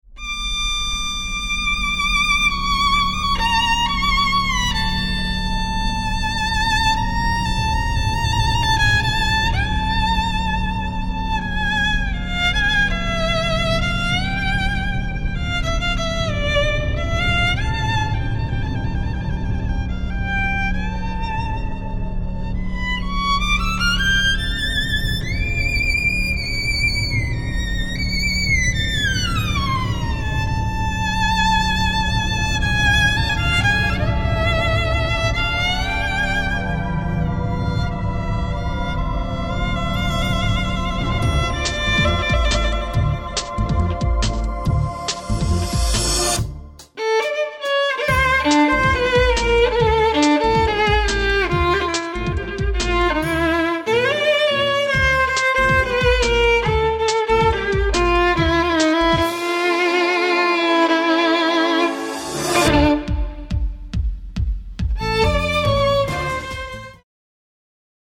String Angels Electric String Trio
The String Angels features three beautiful girls, three electric violins, one dynamite show!
From the creators of The Three Waiters, this glamorous trio will amaze your guests with their fully choreographed, interactive electric violin show, supported by fully pumped orchestral backings with a beat as they get up close and personal into the audience.